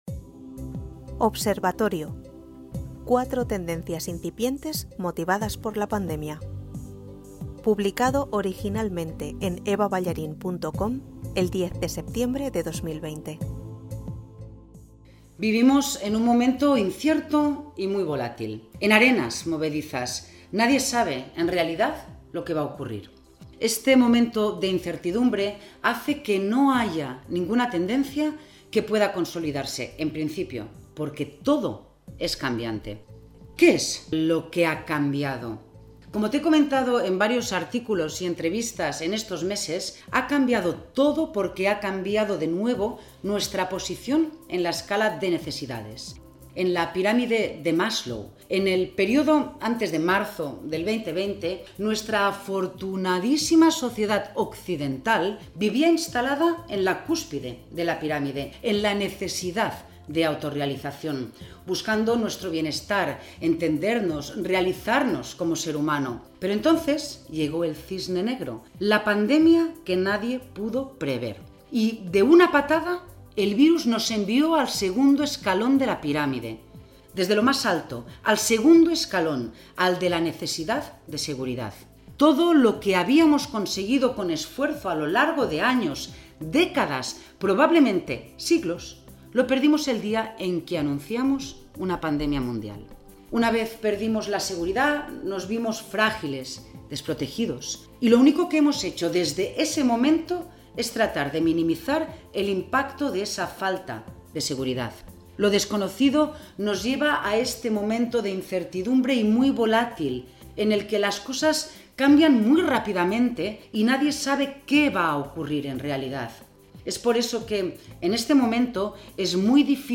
Si lo prefieres, te lo leo Vivimos en un momento incierto y muy volátil.